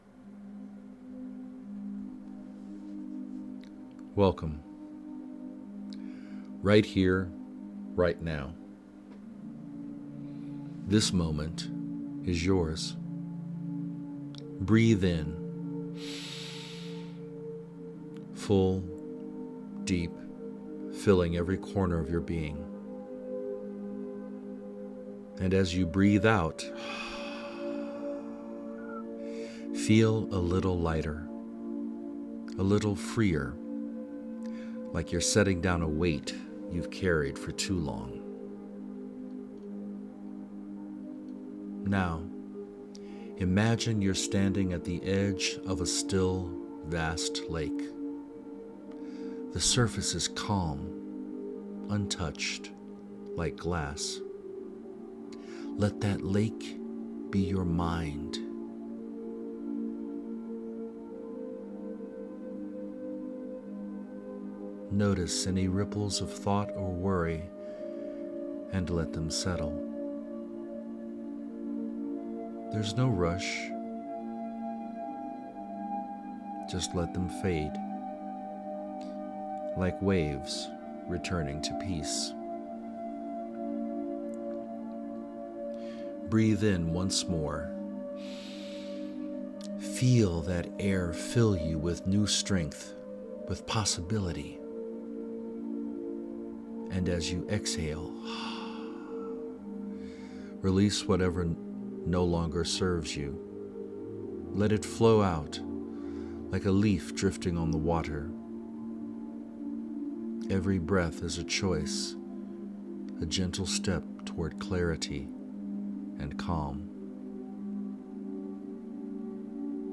Guided Meditation for Finding Strength in Resilience